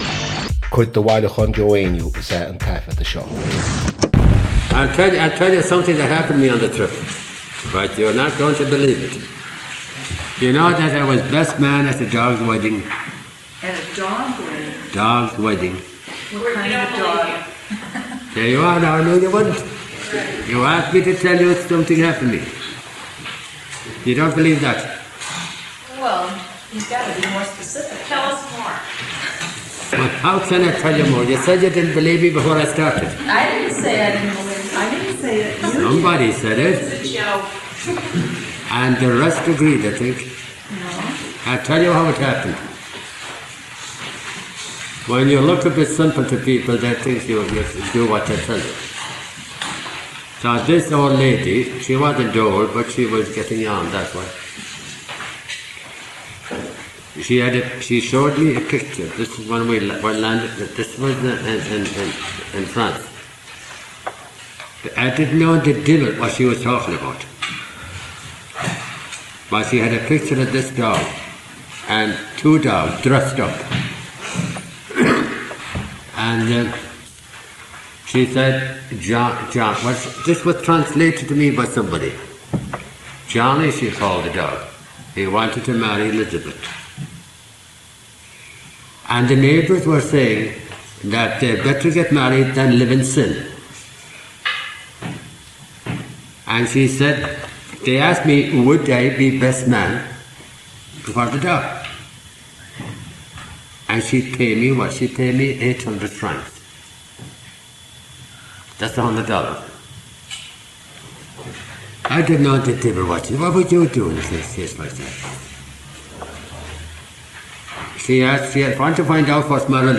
• Catagóir (Category): story.
• Suíomh an taifeadta (Recording Location): University of Washington, United States of America.
• Ocáid an taifeadta (Recording Occasion): evening class.
Having recently returned from a trip to France, Joe was questioned by students about his trip.